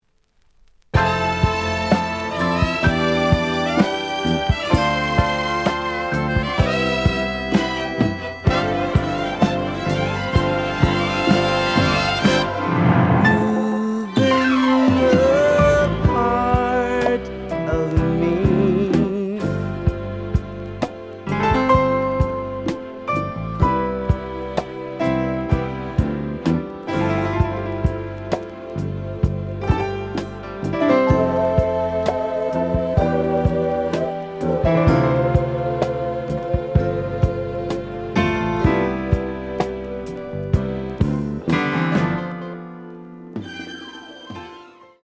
The audio sample is recorded from the actual item.
●Genre: Soul, 70's Soul
Slight edge warp. But doesn't affect playing. Plays good.)